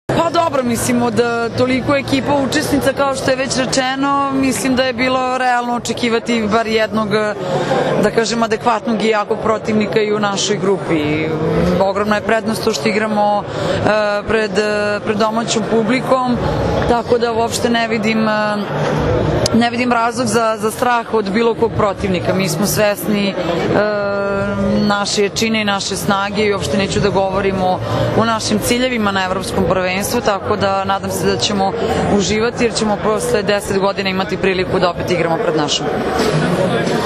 Izjava Maje Ognjenović
zre-ognjenovic.WMA